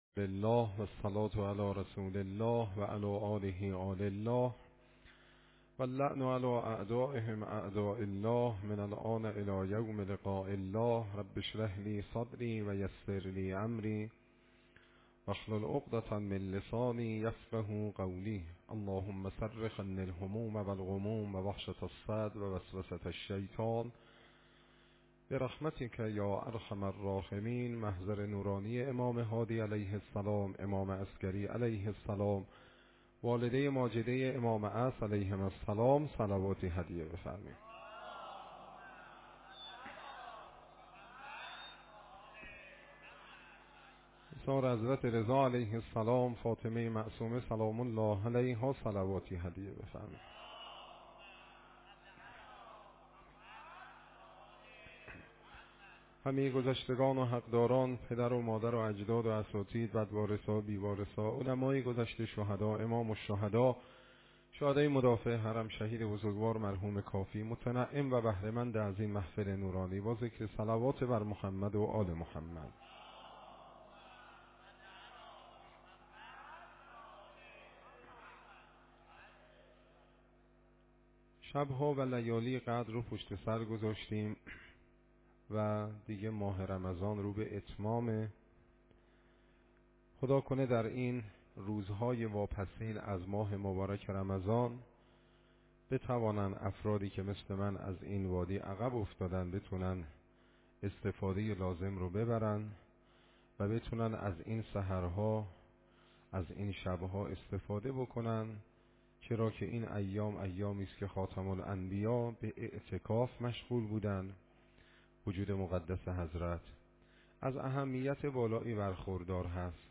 شب 25 رمضان 97 - مهدیه تهران